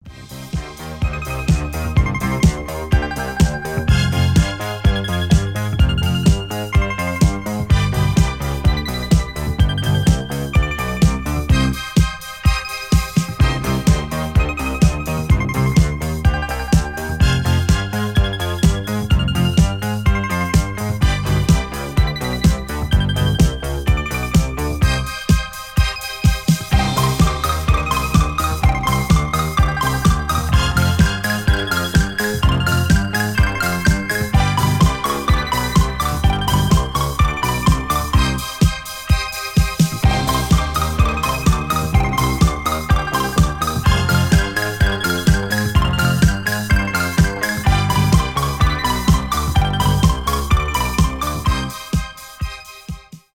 Танцевальные рингтоны
диско без слов
electronic 80-е